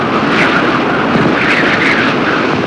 Whipping Wind (short) Sound Effect
Download a high-quality whipping wind (short) sound effect.
whipping-wind-short.mp3